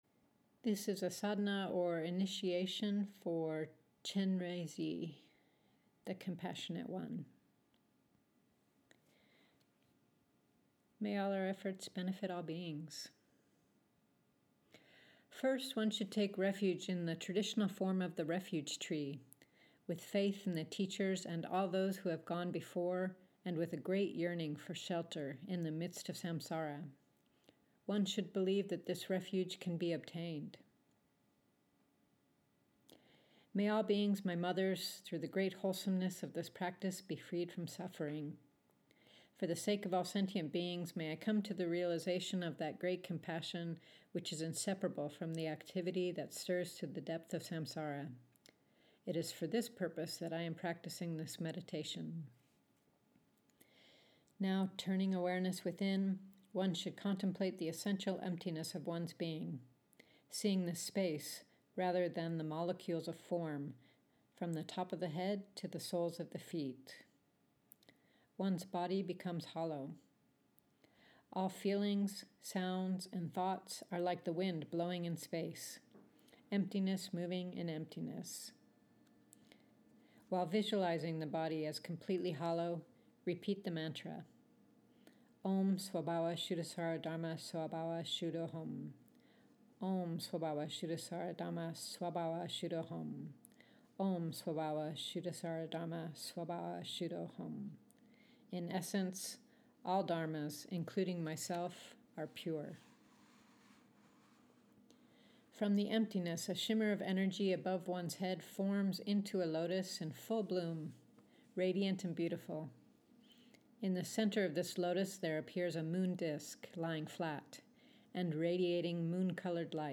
Chenrezi Guided Meditation